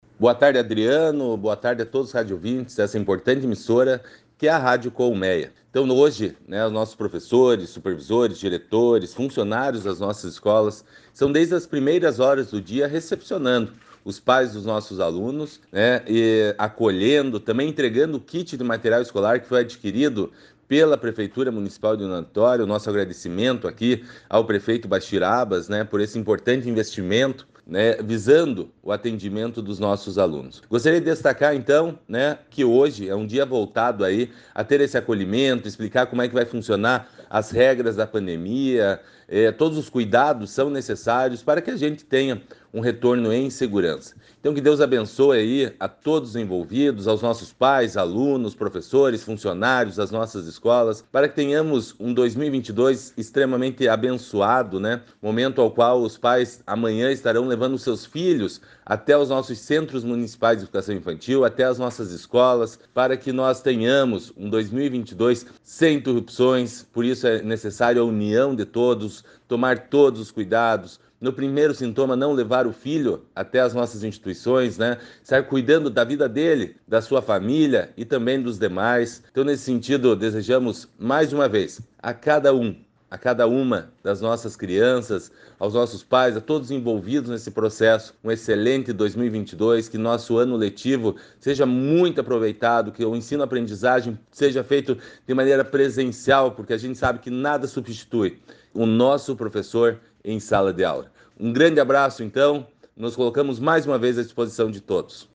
Acompanhe o áudio do secretário abaixo: